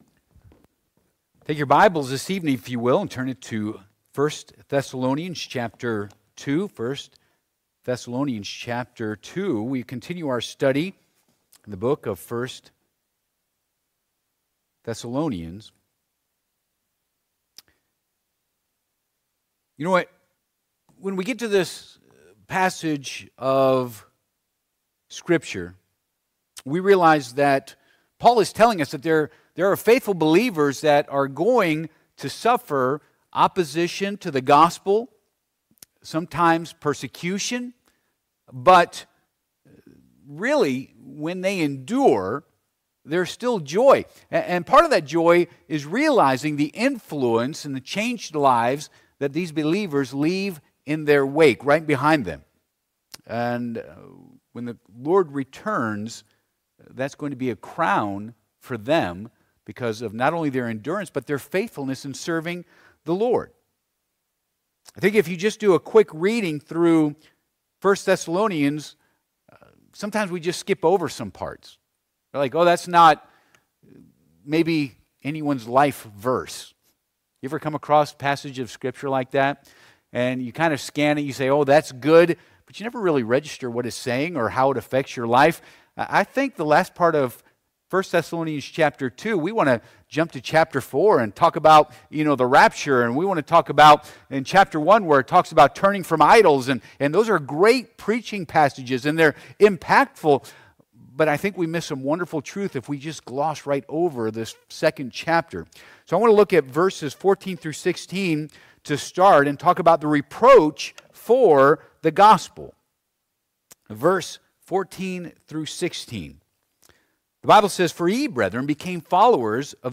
Passage: I Thess. 2:14-18 Service Type: Midweek Service